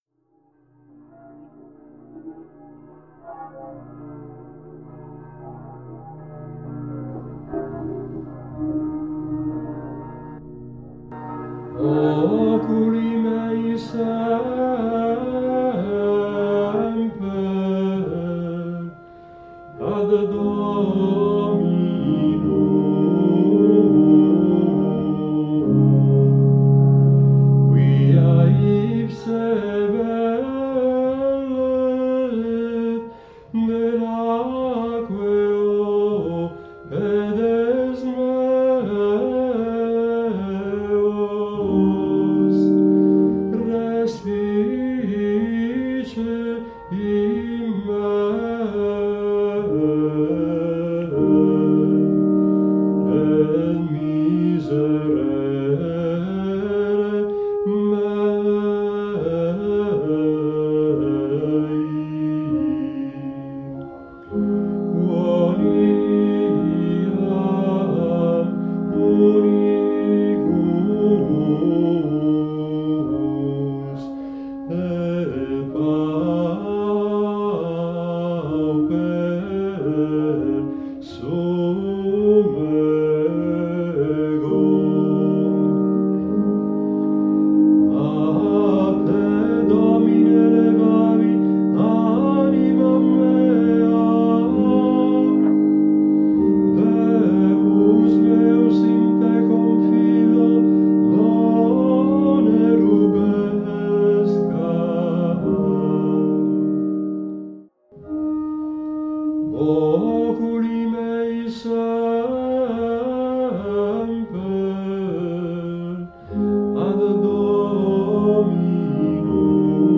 Ar Sent / Les Saints, Gregorian, Liderezh ar Sulioù hag ar gouelioù / Liturgie des Dimanches et fêtes, Sulioù ha gouelioù